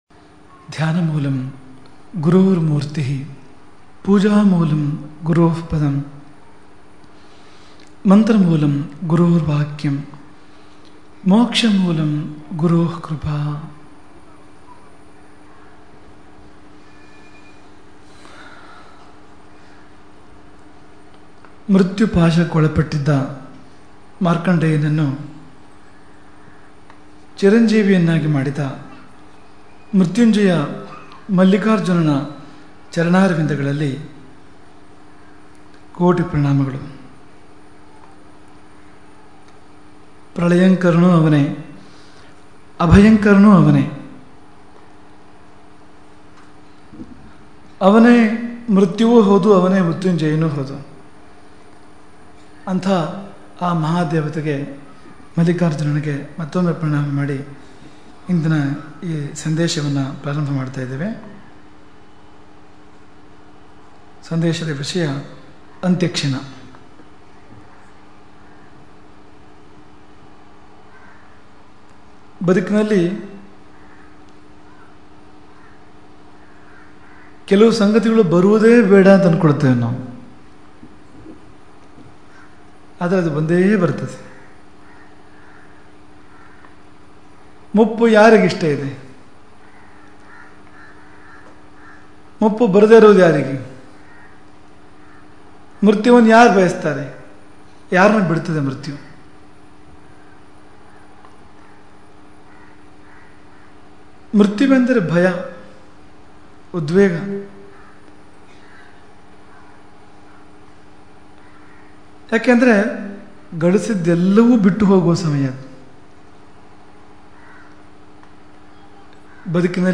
ಶ್ರೀಕ್ಷೇತ್ರ ಗೋಕರ್ಣದ ಅಶೋಕೆಯಲ್ಲಿ ಸಂಪನ್ನಗೊಂಡ ವಿಕೃತಿ ಸಂವತ್ಸರ ಚಾತುರ್ಮಾಸ್ಯದ ಶುಭಾವಸರದಲ್ಲಿ, ಶ್ರೀ ಶ್ರೀ ರಾಘವೇಶ್ವರ ಭಾರತೀ ಮಹಾಸ್ವಾಮಿಗಳವರ ದಿವ್ಯ ವಚನಾಮೃತಧಾರೆಯಲ್ಲಿ ಹರಿದು ಬಂದ ಪ್ರವಚನ ಮಾಲಿಕೆ